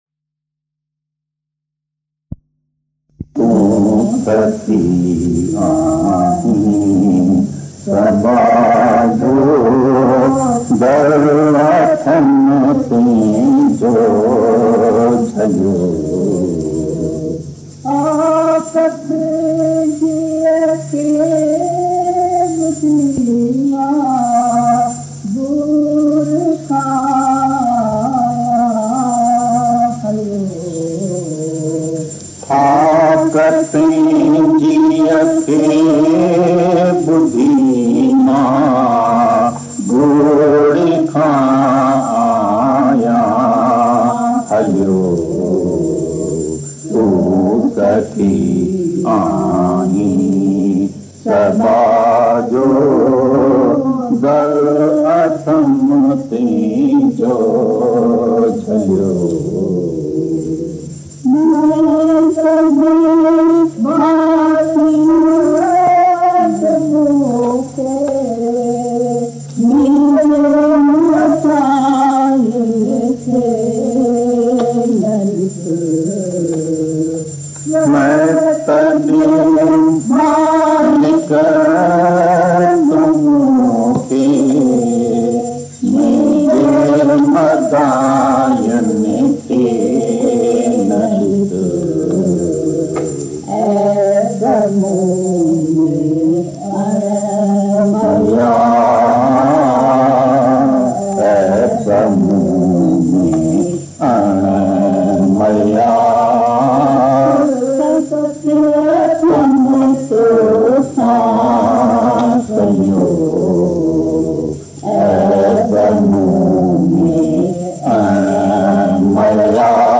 Tu Sakhi Aahe Sabjho Daru Bhajan | तू सखी आही सबझो दरू भजनDivine Shyam Bhagwan Bhajans